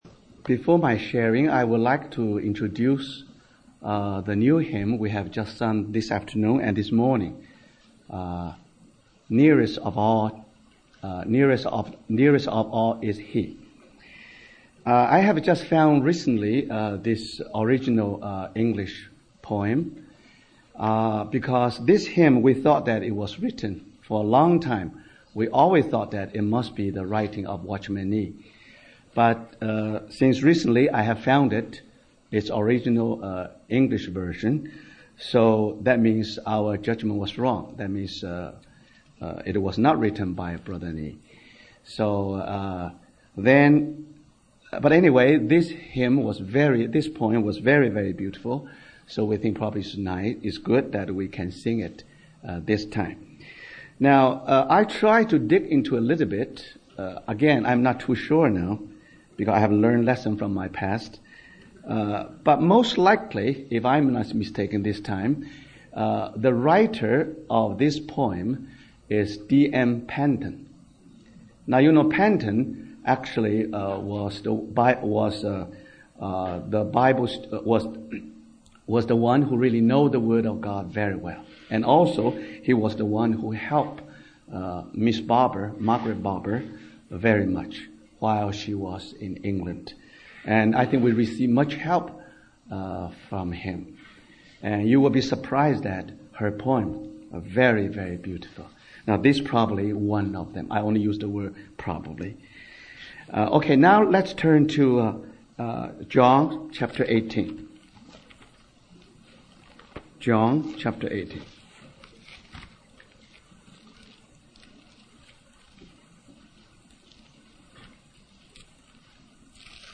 Harvey Cedars Conference